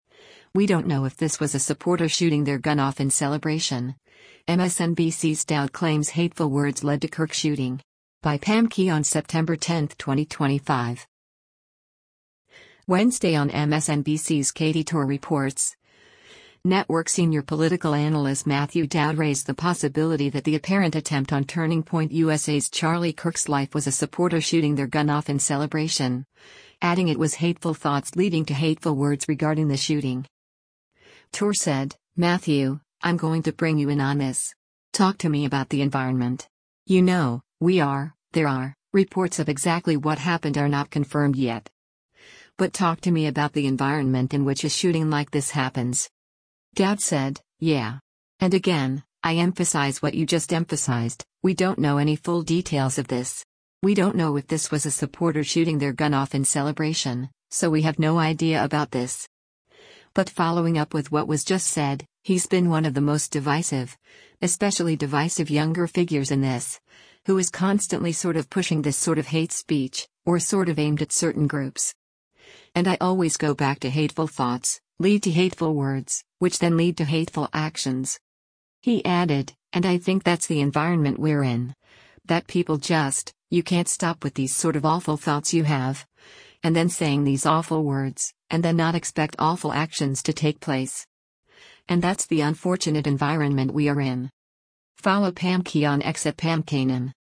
Wednesday on MSNBC’s “Katy Tur Reports,” network senior political analyst Matthew Dowd raised the possibility that the apparent attempt on Turning Point USA’s Charlie Kirk’s life was a “supporter shooting their gun off in celebration,” adding it was “hateful thoughts” leading to “hateful words” regarding the shooting.